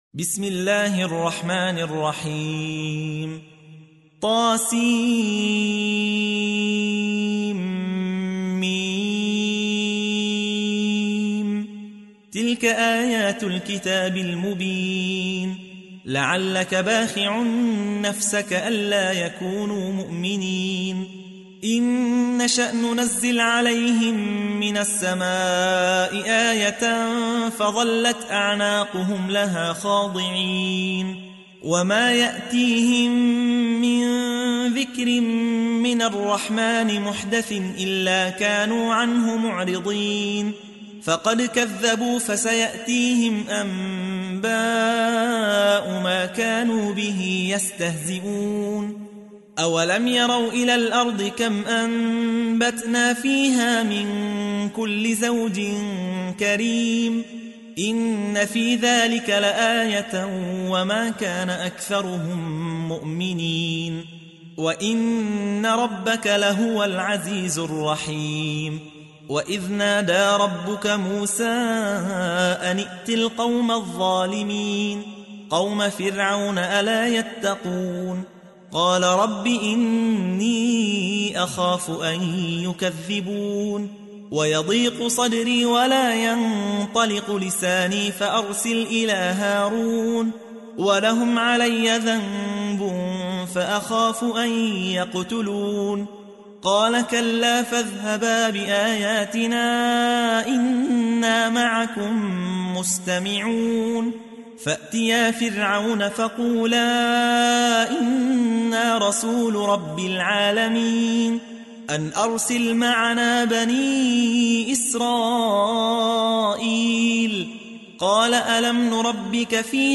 تحميل : 26. سورة الشعراء / القارئ يحيى حوا / القرآن الكريم / موقع يا حسين